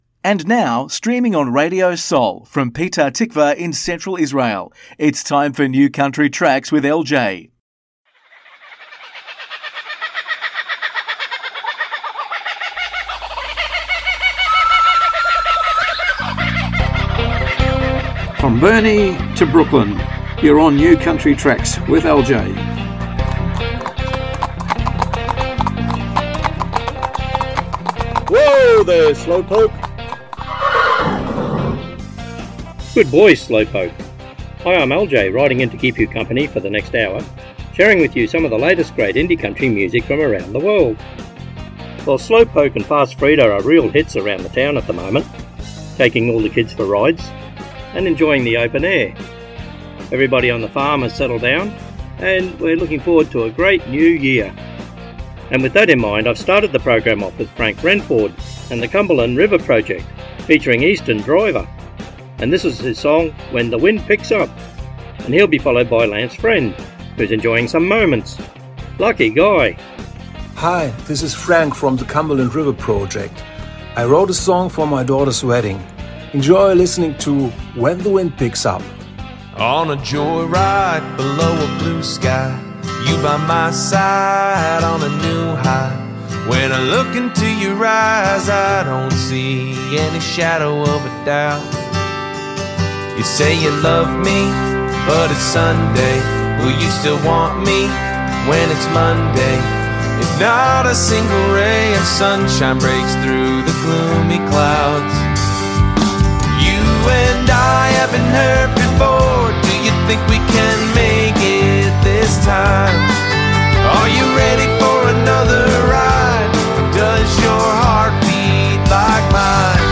מוזיקת קאנטרי ואינדי עולמית - התכנית המלאה 28.12.24